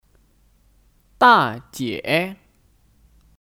大姐 (Dàjiě 大姐)